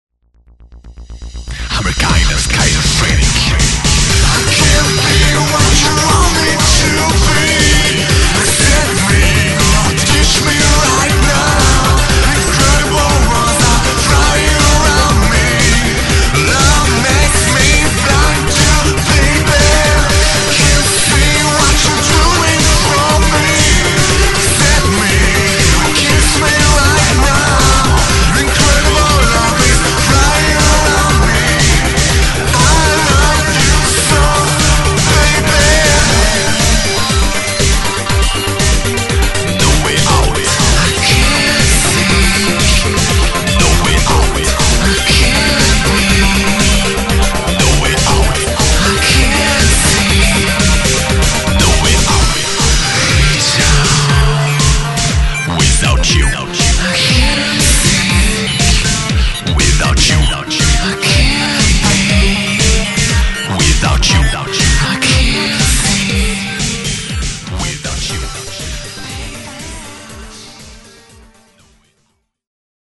All the following songs/samples have been degraded.